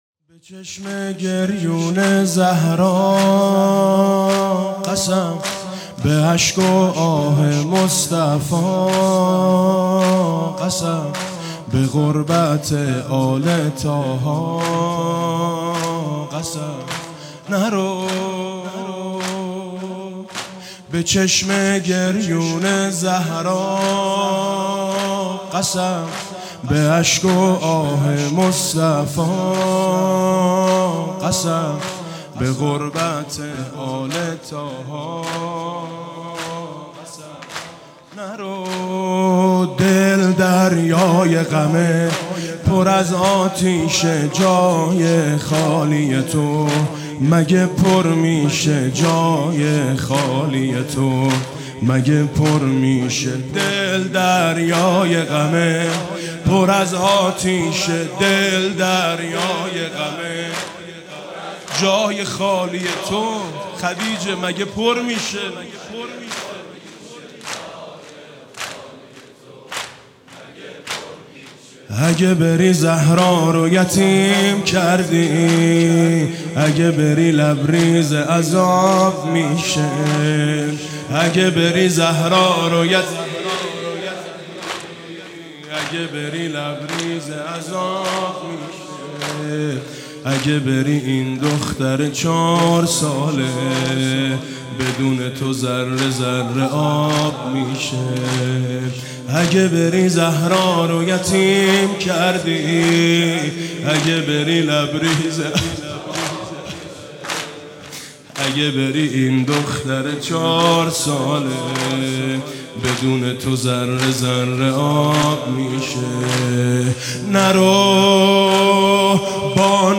رمضان 98
مسجد شهید بهشتی 26 اردیبهشت-زمینه تنها دلخوشی نبی و زهرا ماه رمضان حاج میثم مطیعی